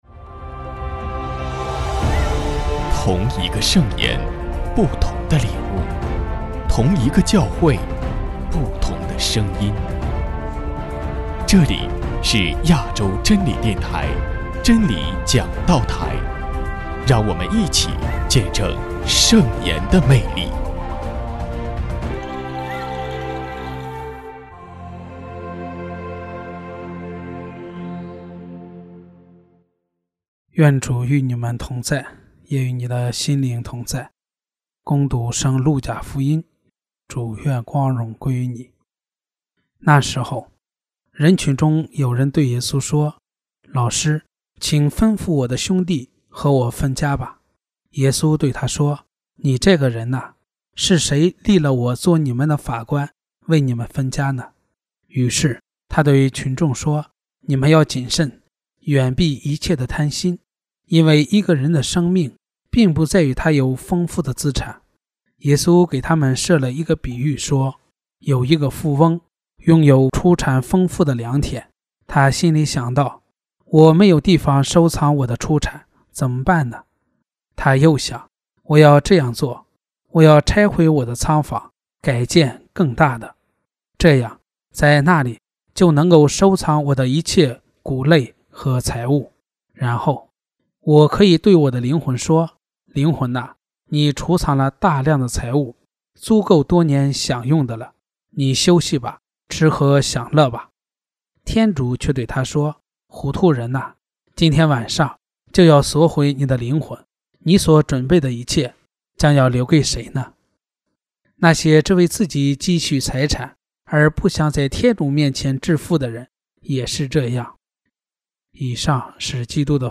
首页 / 真理讲道台/ 证道/ 丙年